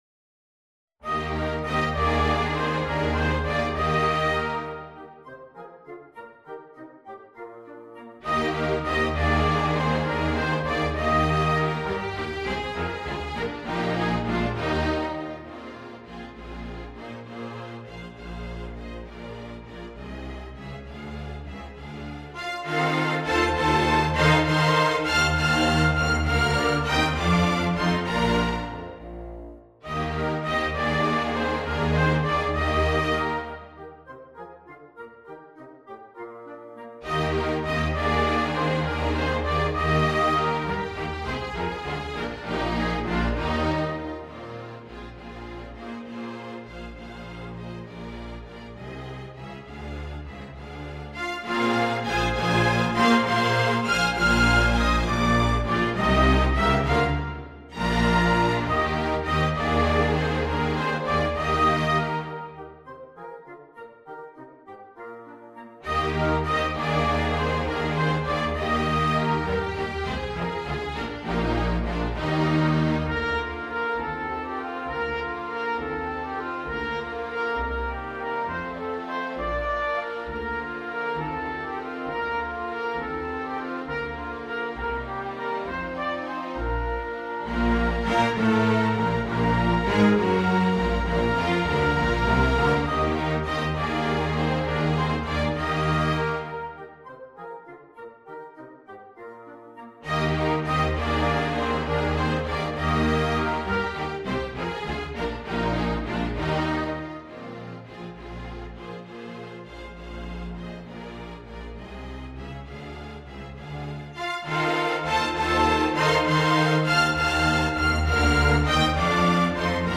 for orchestra
Strings (Violin 1, Violin 2, Viola, Cello, Bass)